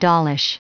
Prononciation du mot dollish en anglais (fichier audio)
Prononciation du mot : dollish